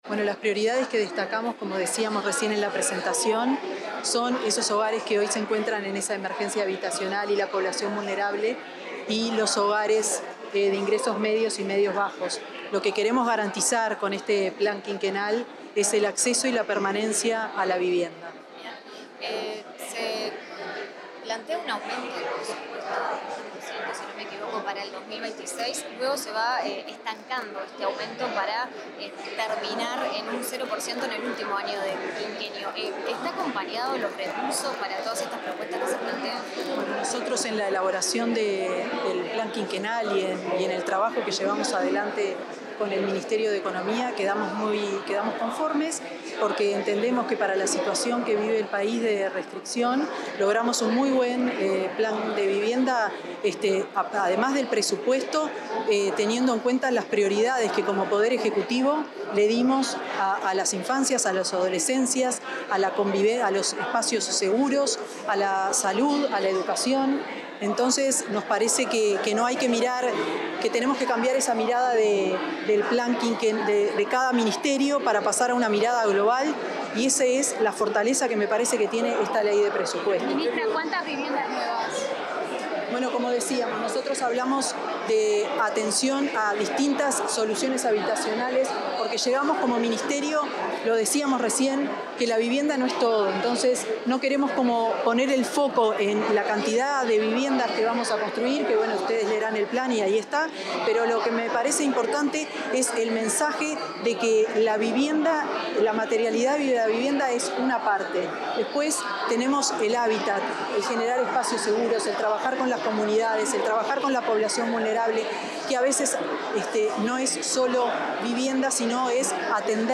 Declaraciones de la ministra de Vivienda y Ordenamiento Territorial, Tamara Paseyro
La ministra de Vivienda y Ordenamiento Territorial, Tamara Paseyro, realizó declaraciones luego de la presentación del Plan Quinquenal de Vivienda y